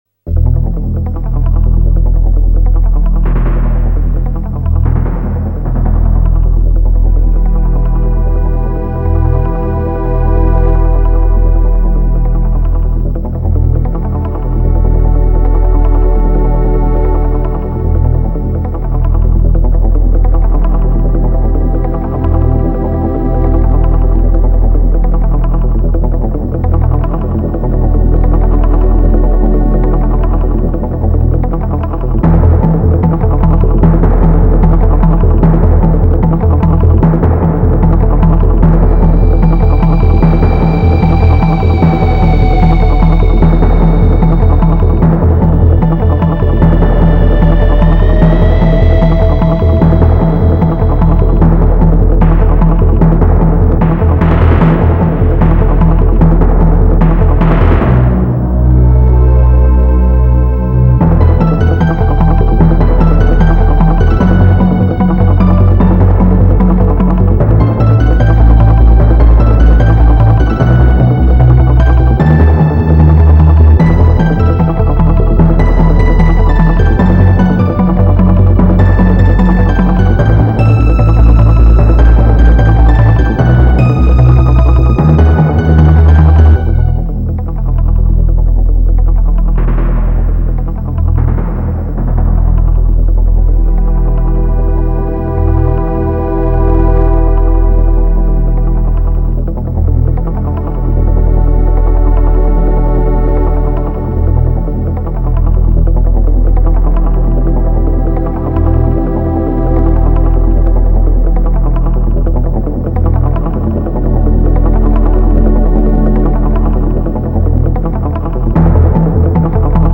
BPM150-150
Audio QualityMusic Cut